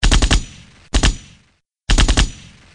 AK47 Assault Rifle
The sound of an AK47 Assault Rifle being fired in burst. Great military weapon sound effects.